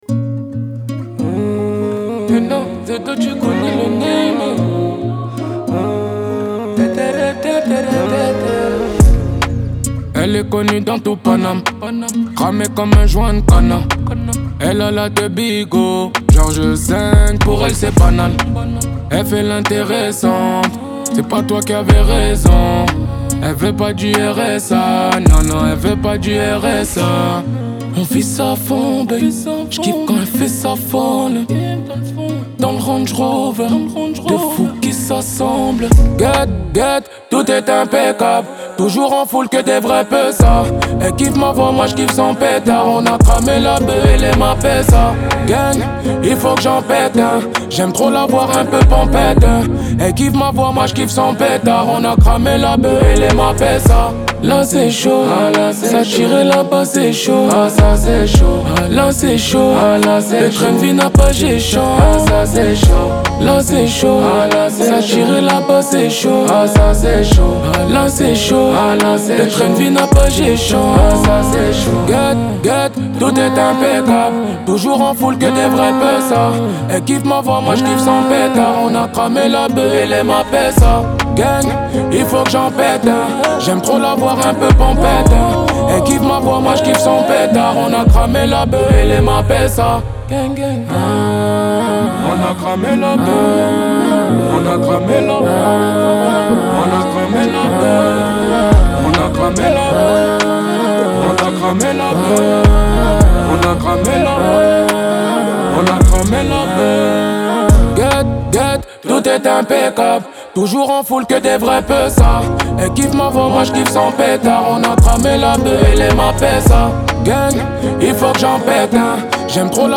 Genres : pop urbaine, french rap, french r&b